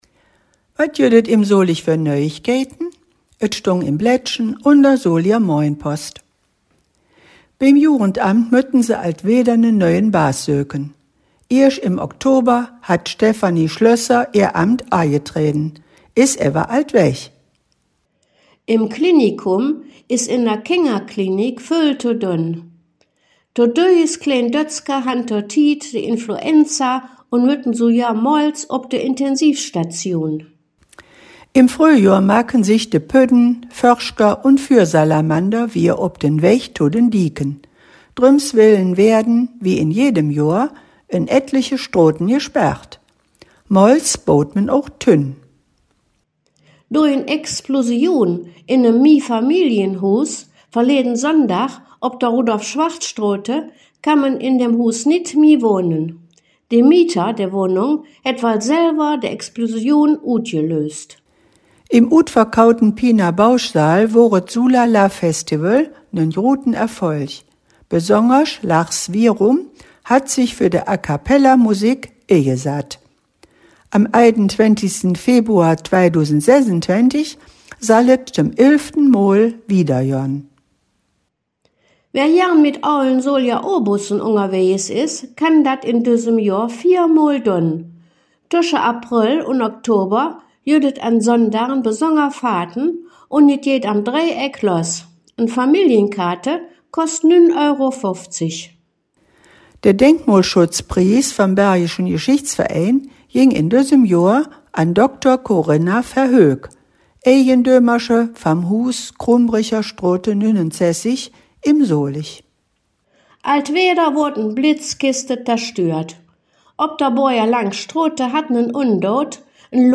Nöüegkeïten op Soliger Platt - Nachrichten in Solinger Platt
In dieser Folge „Dös Weeke em Solig“ blicken de Hangkgeschmedden in Solinger Platt auf die Nachrichten vom 14. Februar 2025 bis zum 28. Februar 2025 zurück.